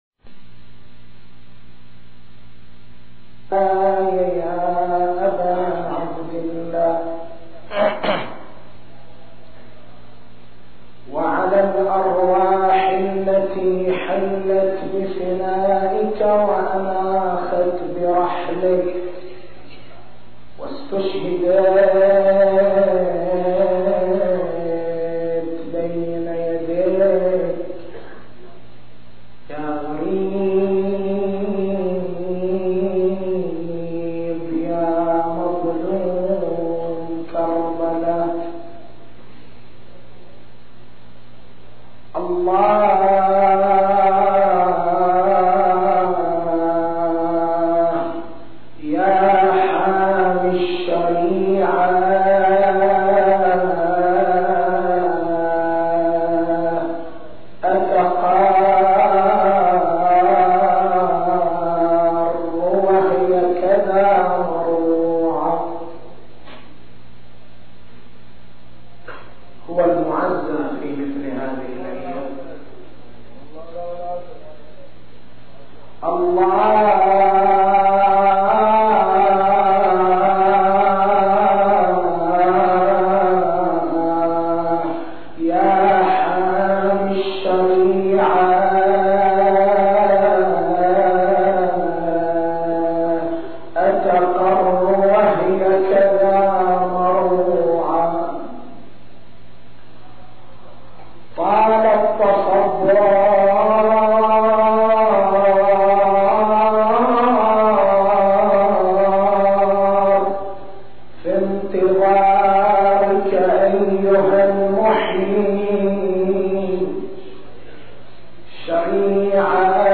تاريخ المحاضرة: 13/01/1425 نقاط البحث: المقصود من مرض الجفاف العاطفي أسباب مرض الجفاف العاطفي كيفية علاج مرض الجفاف العاطفي التسجيل الصوتي: تحميل التسجيل الصوتي: شبكة الضياء > مكتبة المحاضرات > محرم الحرام > محرم الحرام 1425